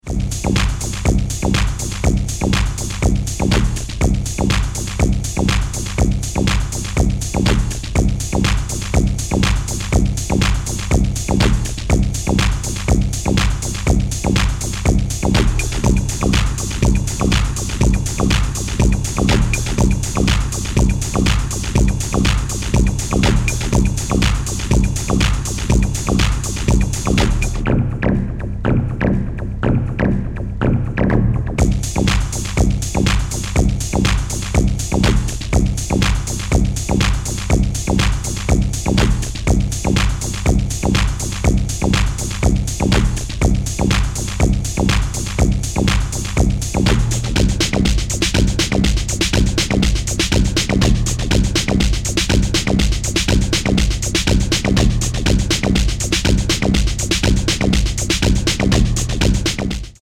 Raw & minimal, Chicago-inspired obscure housetracks